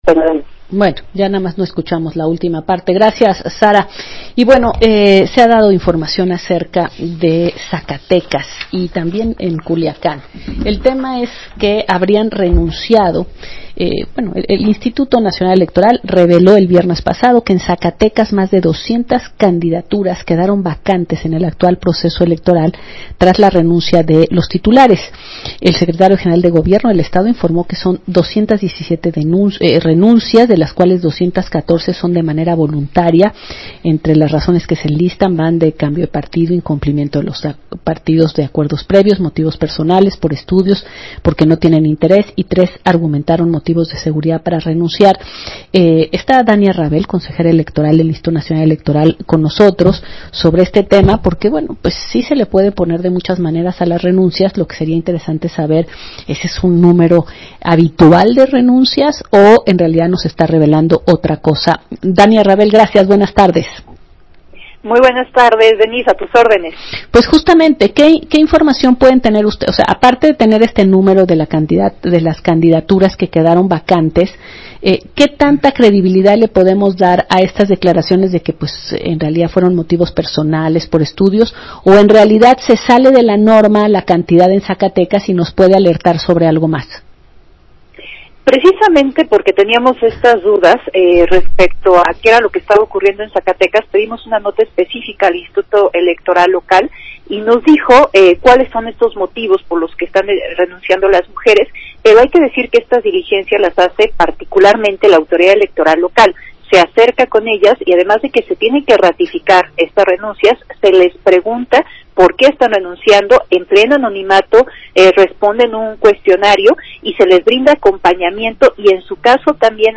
Escucha aquí el audio de la entrevista